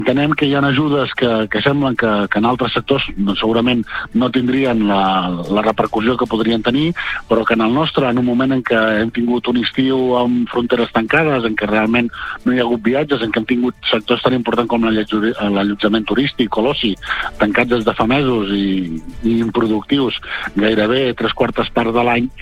Entrevistes SupermatíCastell - Platja d'Aro